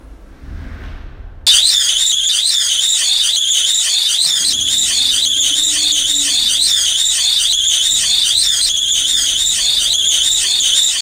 黒板を引っ掻く音
黒板.m4a